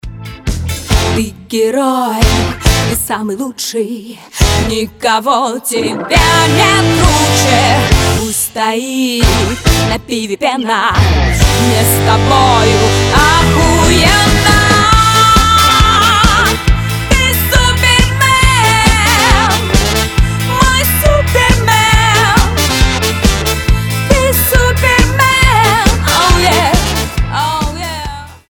громкие
веселые
труба